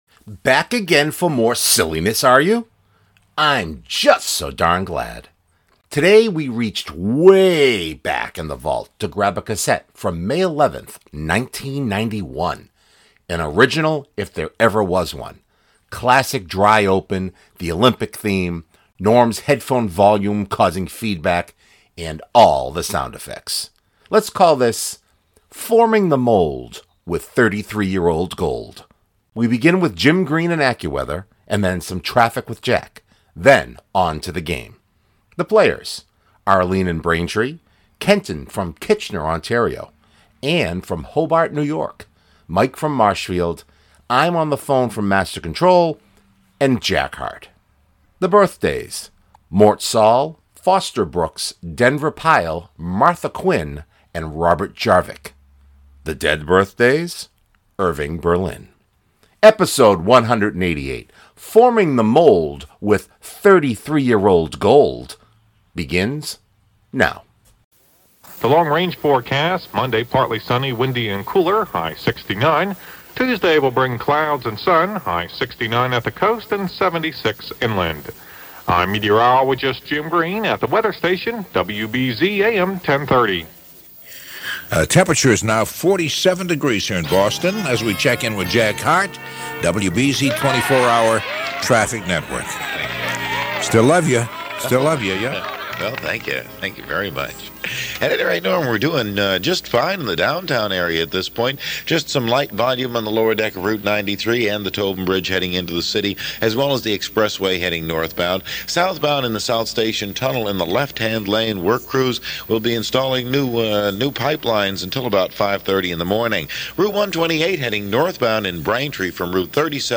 Today we reached waaaaay back in the Vault to grab a cassette from May 11th, 1991.